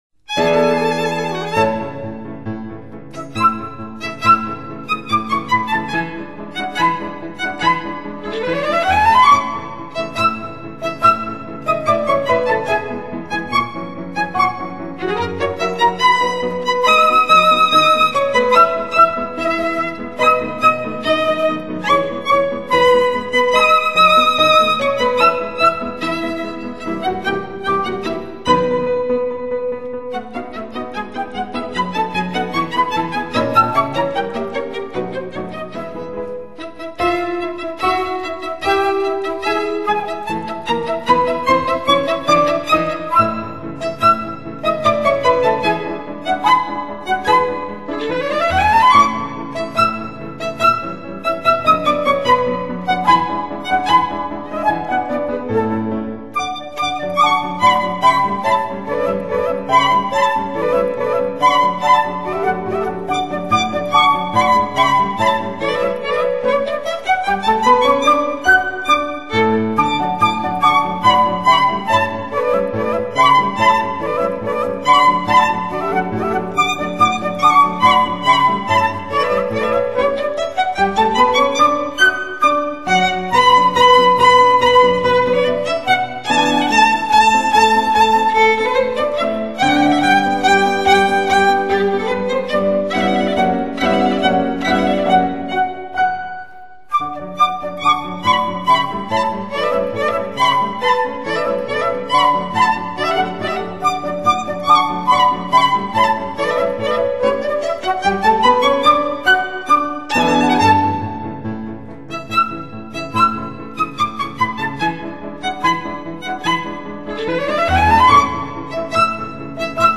如痴如醉的旋律魅力 缤纷多姿的音乐景致
传承帕格尼尼超炫琴技 展现如火一般燃情乐章
激情燃烧 华彩纷呈的声音魅力 技惊四座 纤毫毕现的演奏技巧